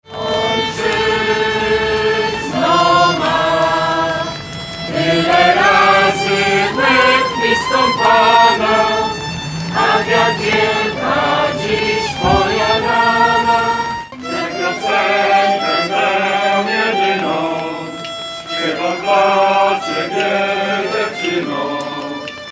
Z tego względu na miejsce tego wydarzenia wybrano Pl. Wojska Polskiego.